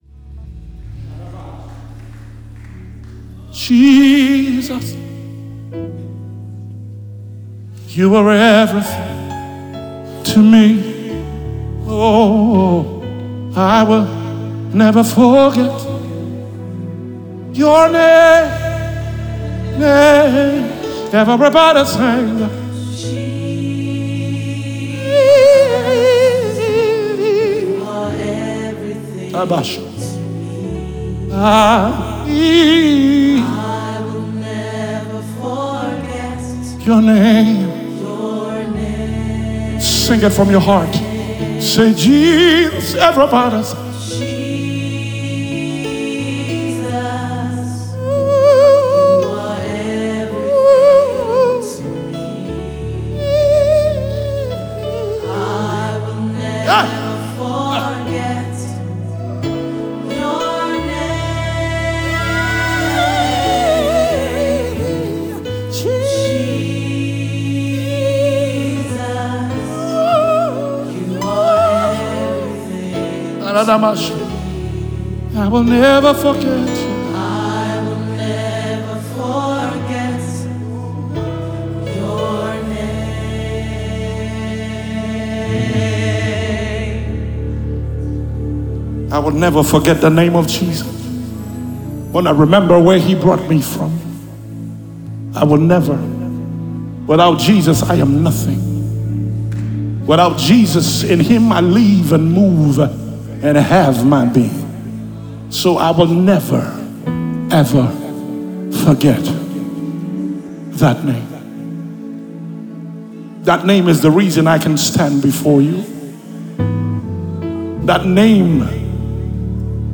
spontaneous worship
Gospel music